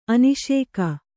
♪ aniṣēka